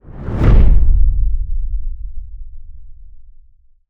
cinematic_deep_low_whoosh_impact_01.wav